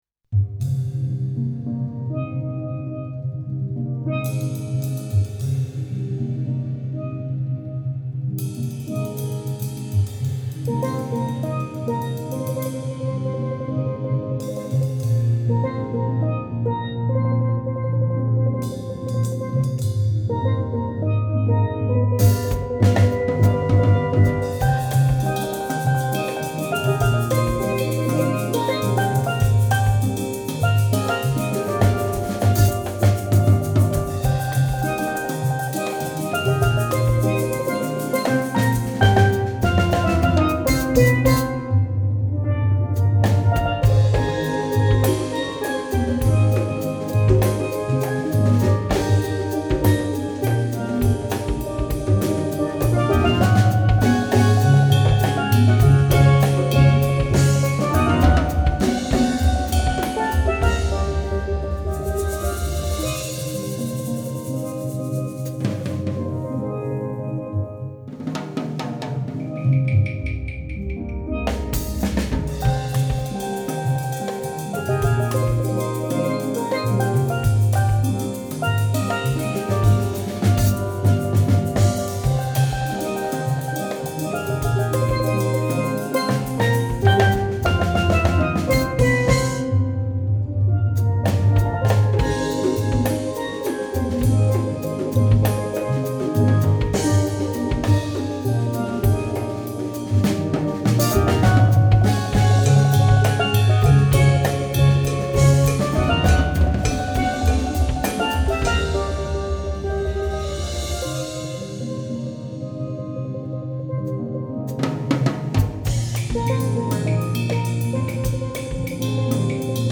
Voicing: Steel Drum